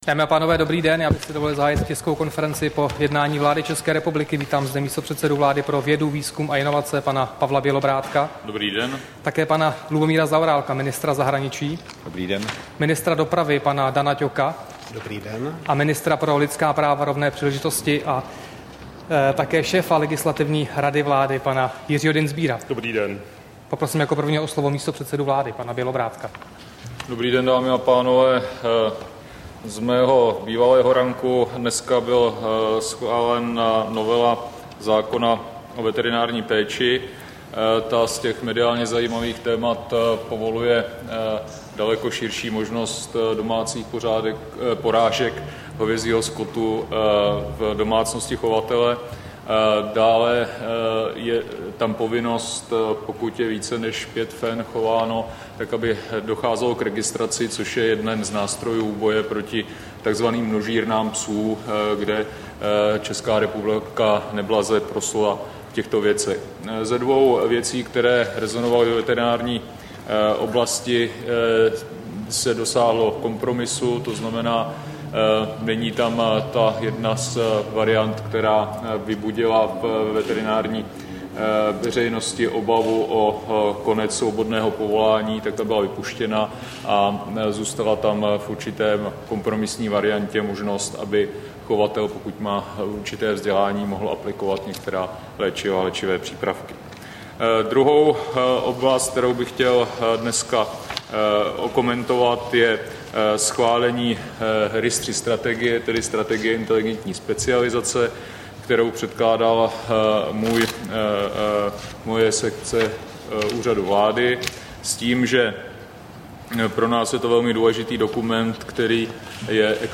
Tisková konference po jednání vlády 11. července 2016